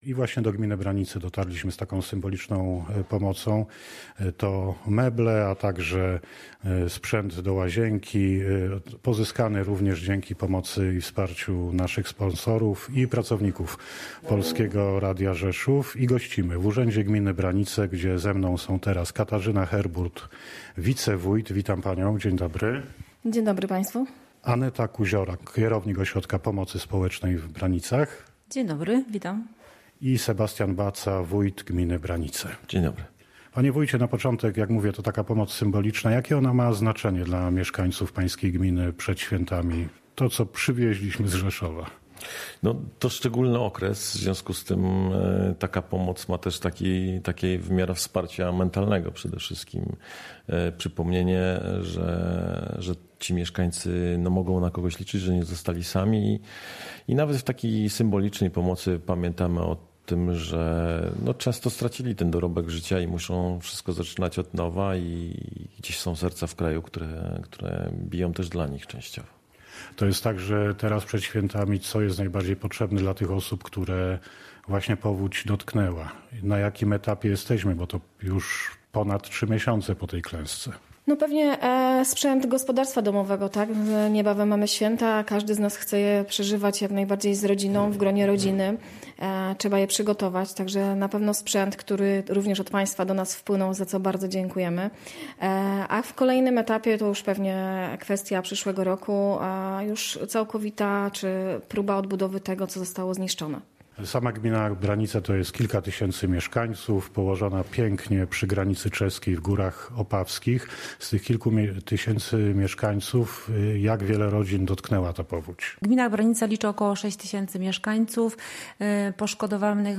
Dary dla powodzian z gminy Branice już na miejscu. Relacja, fotogaleria